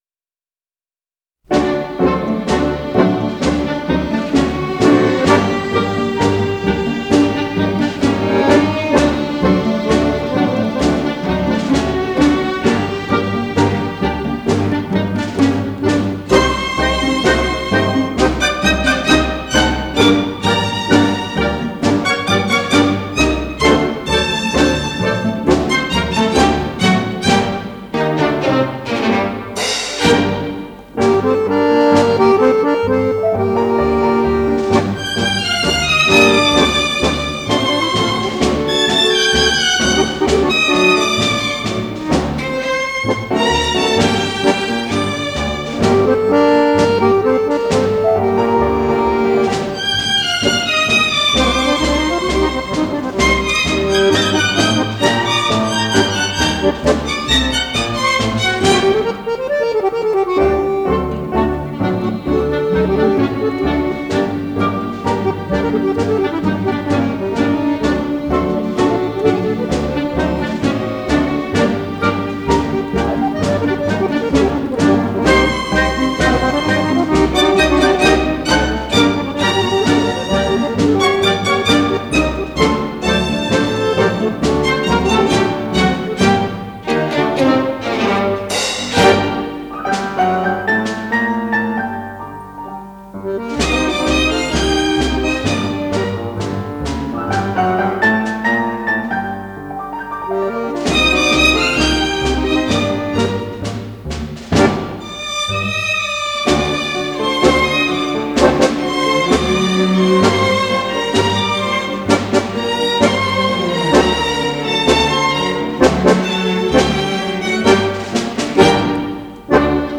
Genre: Tango, Latin